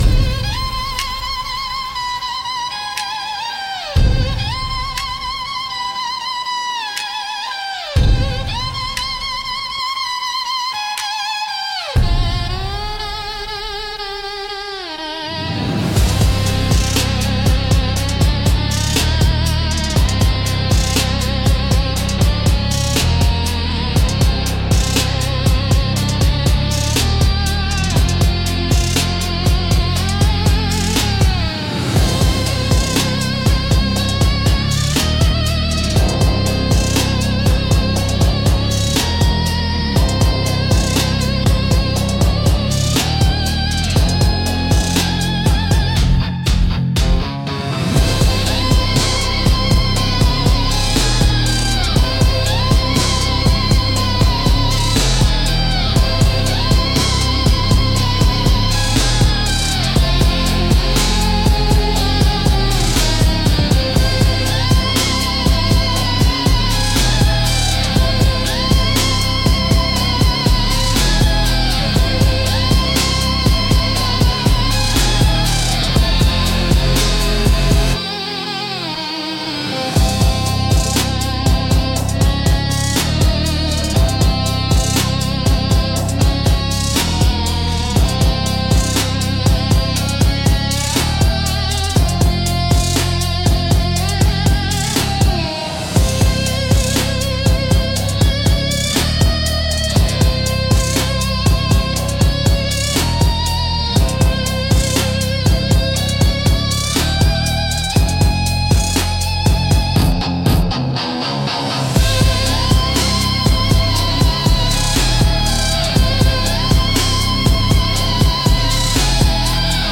Instrumental - Unbuttoned Questions - 3.19